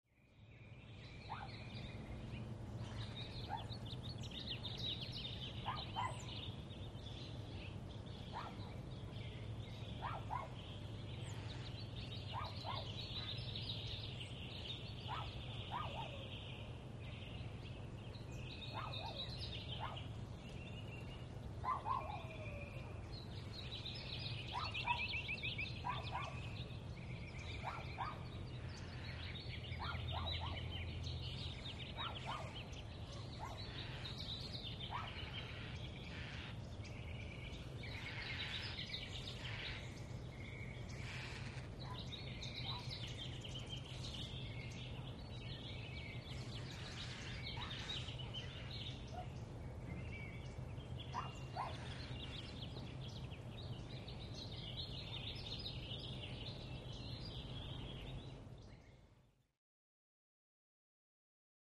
BirdsDayAmbienceB OFS035101
Birds; Day Ambience With Barking Dog, Through Open Window.